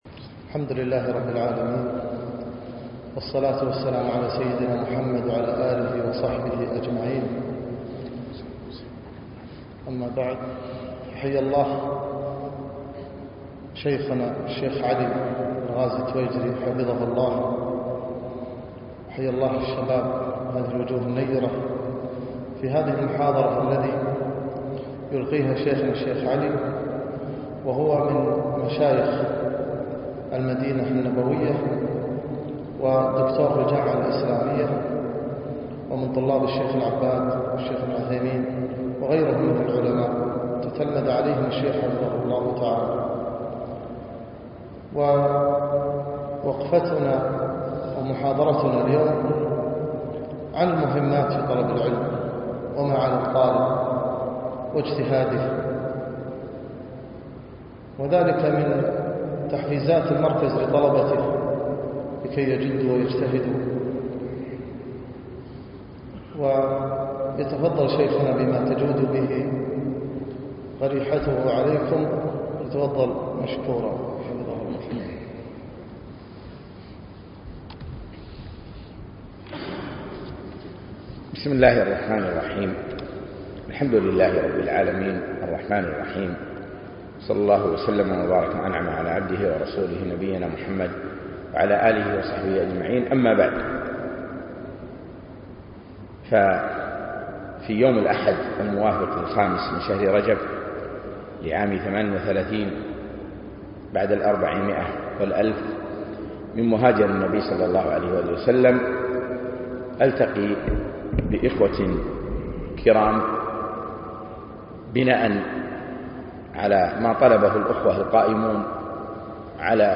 يوم صباح الأحد 2 4 2017 في مركز الثقافي الإسلامي خيطان